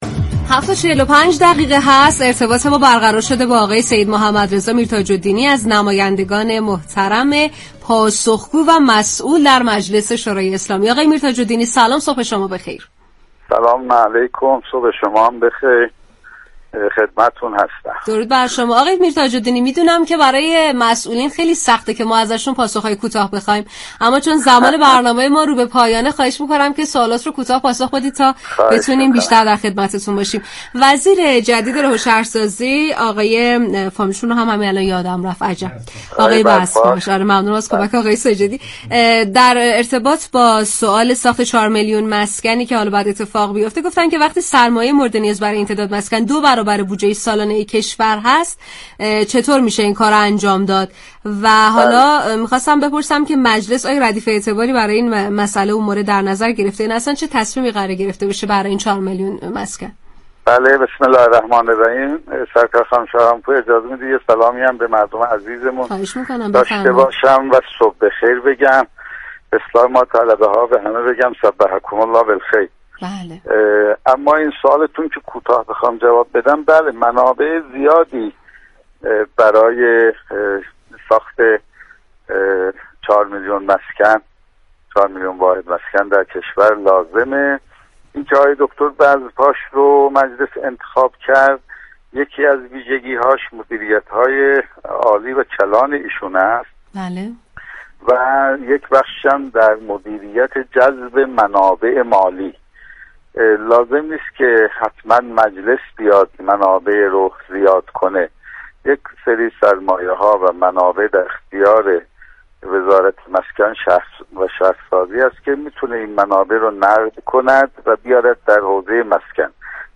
به گزارش پایگاه اطلاع رسانی رادیو تهران، سیدمحمدرضا میرتاج الدینی عضو كمیسیون برنامه و بودجه و محاسبات مجلس شورای اسلامی در گفت و گو با "شهر آفتاب" رادیو تهران گفت: منابع مالی زیادی برای ساخت 4 میلیون واحد مسكن لازم است.